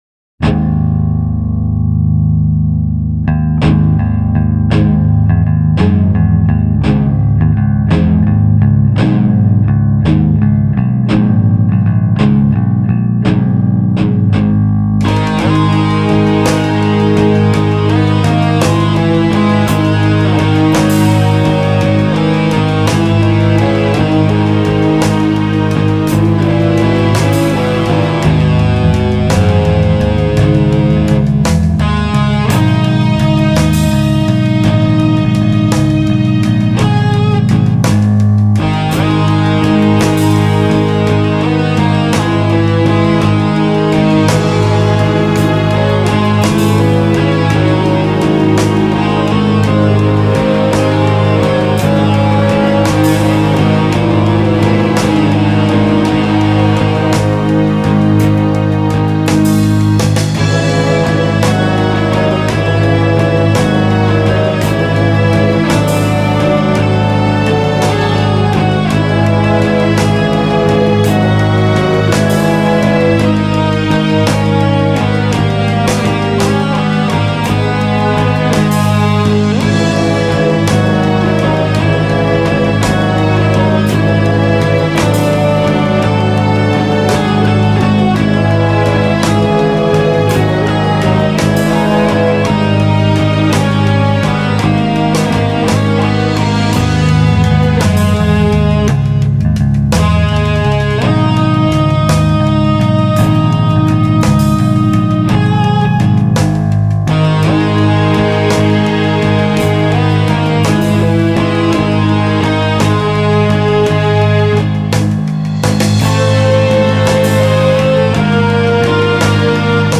А вот и "металлическое" звучание.